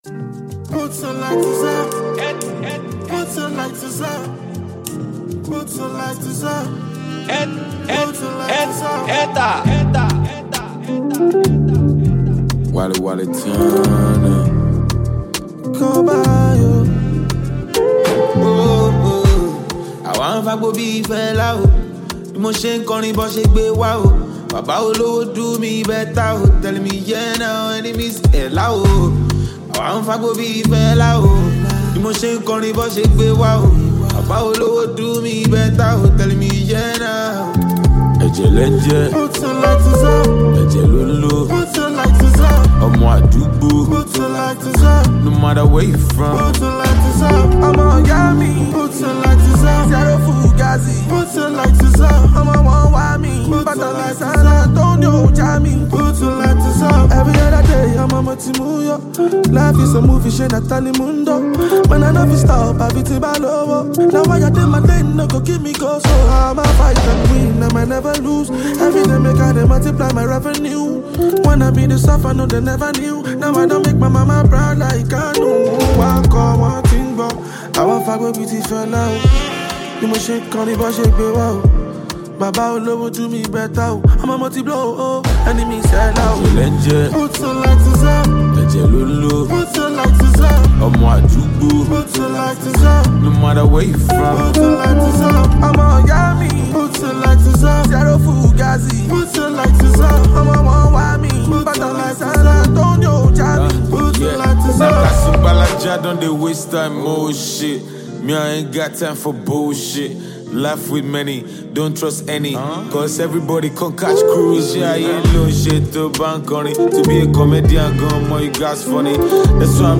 Talented Nigerian rapstar and songwriter